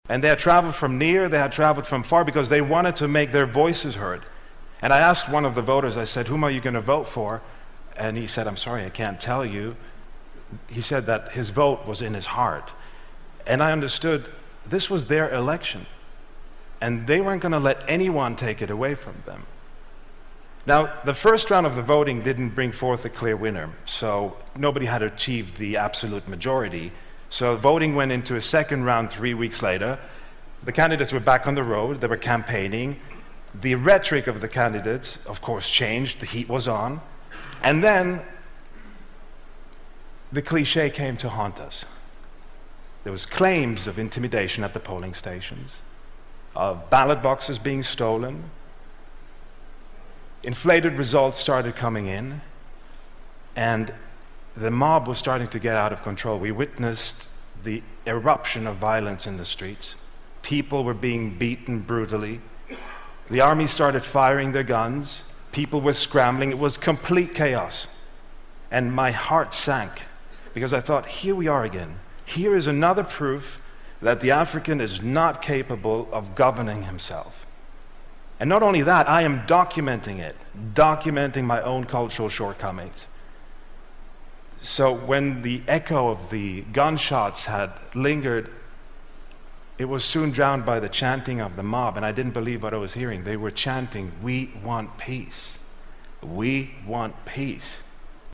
TED演讲:影片纪录加纳民主进程(3) 听力文件下载—在线英语听力室